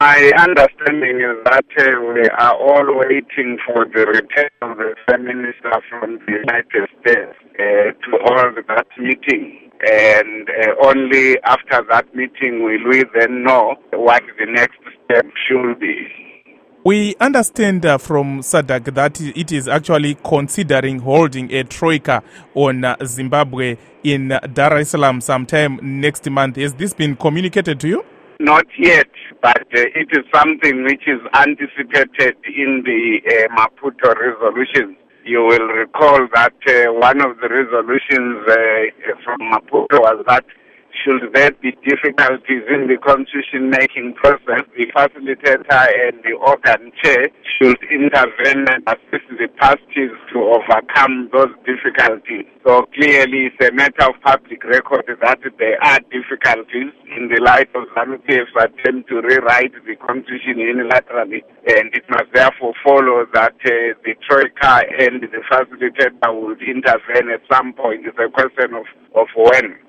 Interview with Welshman Ncube